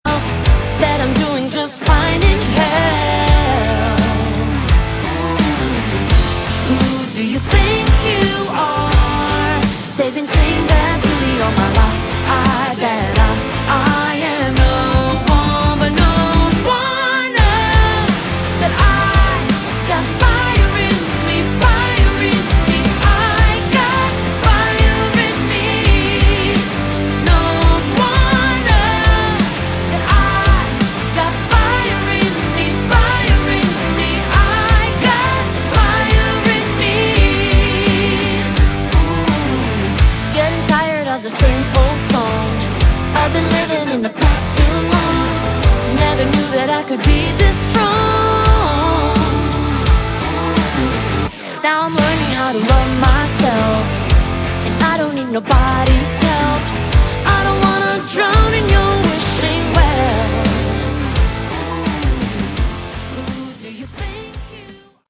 Category: Modern AOR
all instruments, backing vocals
lead vocals, backing vocals, piano